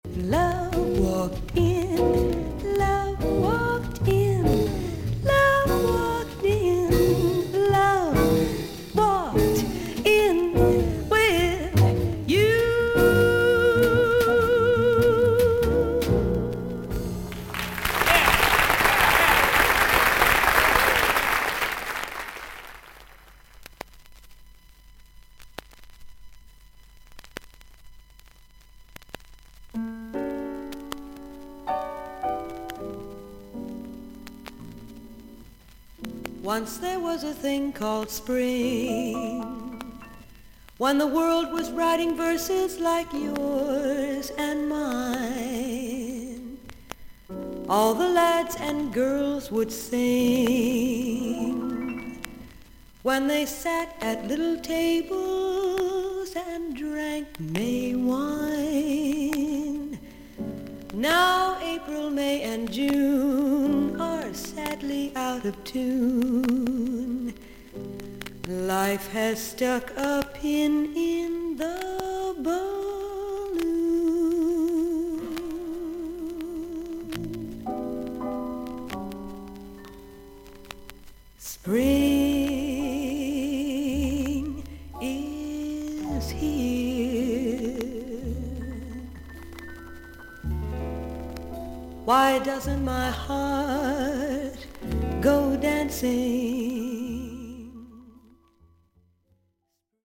ほかはVG+〜VG++:少々軽いパチノイズの箇所あり。少々サーフィス・ノイズあり。クリアな音です。
女性ジャズ・シンガー。ニュー・ヨークのCBSスタジオに少しギャラリーを入れて録音したセカンド・アルバム。